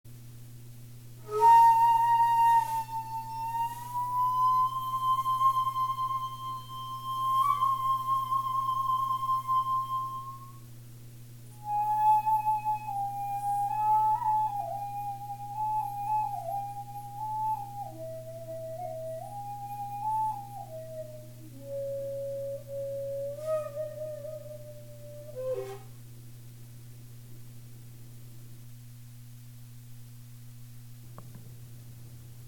実際の楽譜は図Ａです。